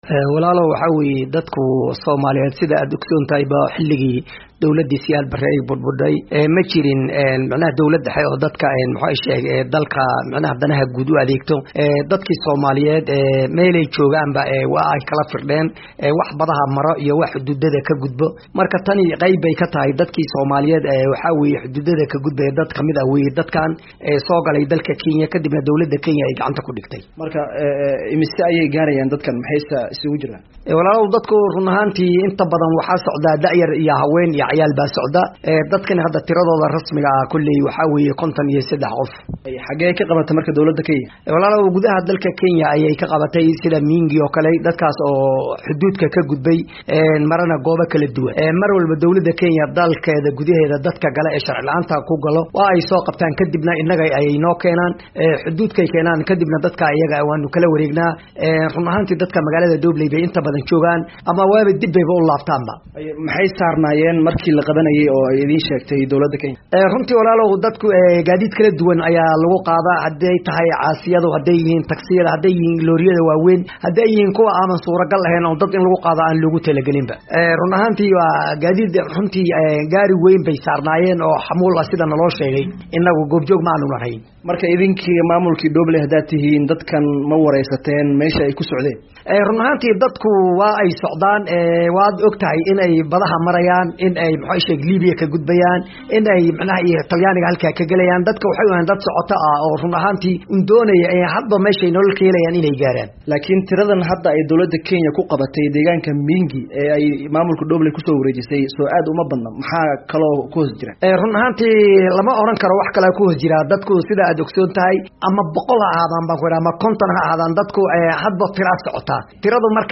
Wareysi: Guddoomiyaha Dhoobley